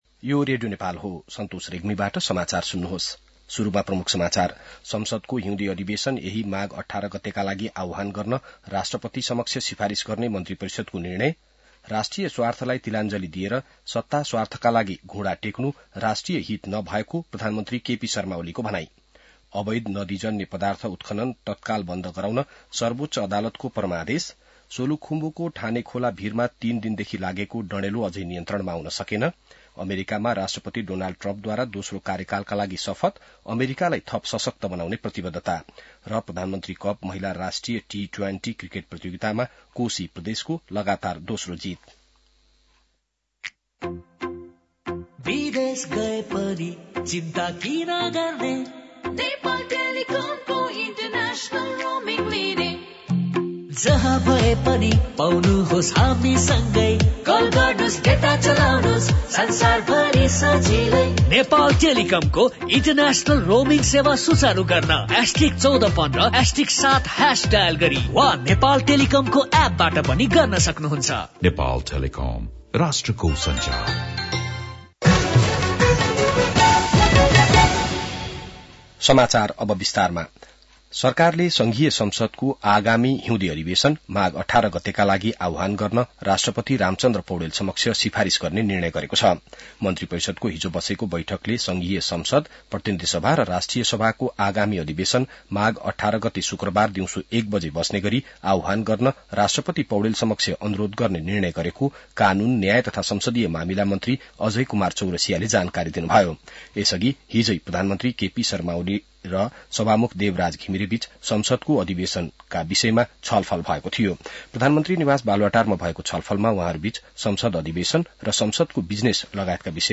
बिहान ७ बजेको नेपाली समाचार : ९ माघ , २०८१